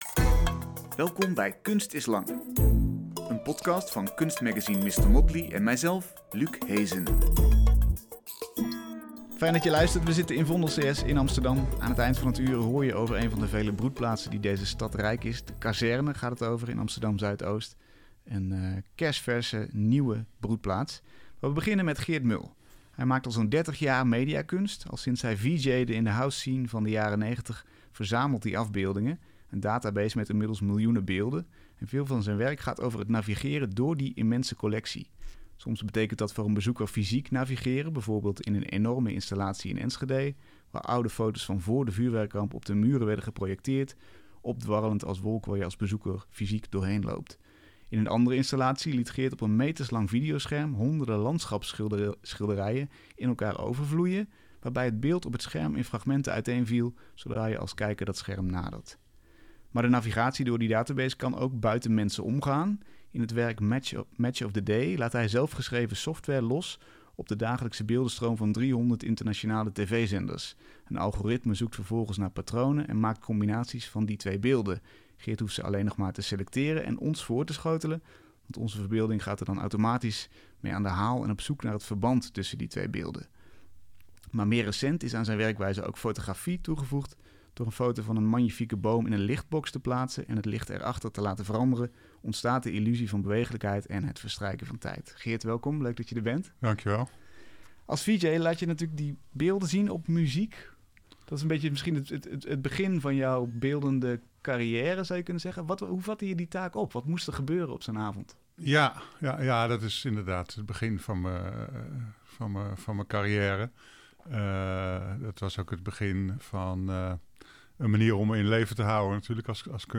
Een gesprek over hoe het hoofd te bieden aan de onstuitbare beeldenstroom die het internet teweeg heeft gebracht, en hoe zijn werk je bewust maakt van a...